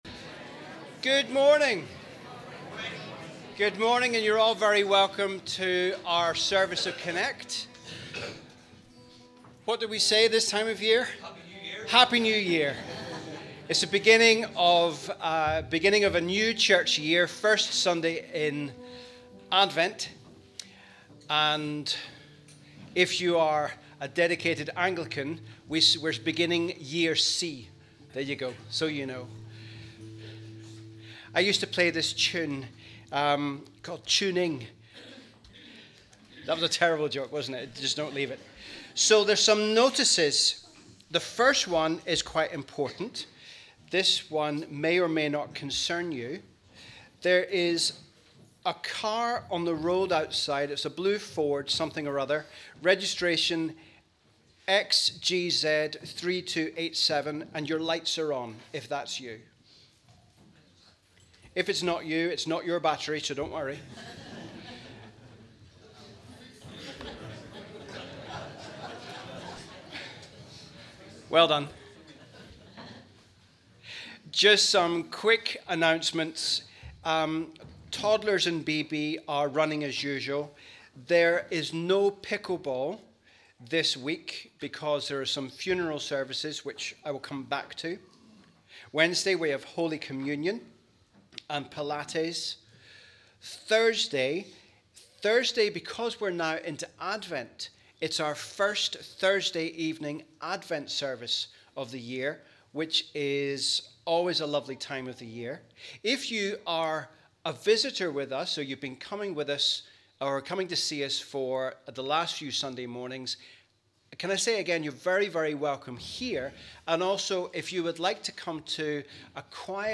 1st December – Advent Sunday Connect Service